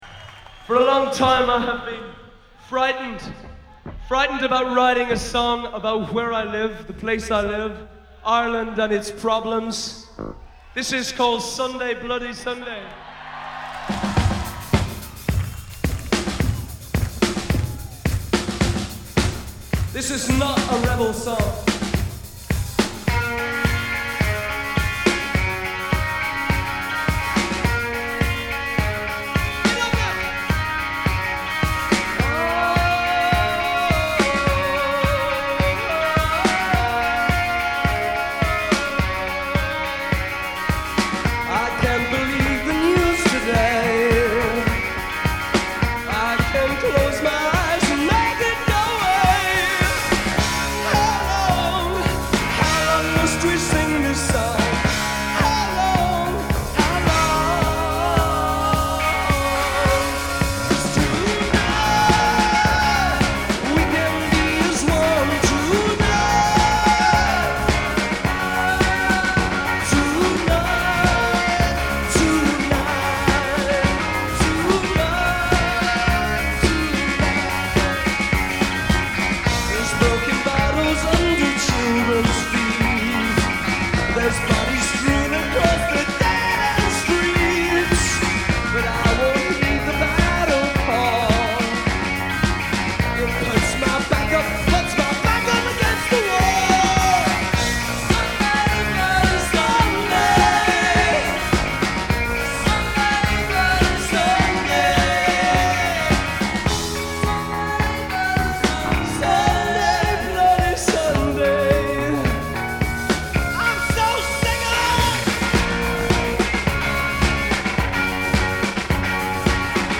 Recorded live at the Hammersmith Palais on 12/6/1982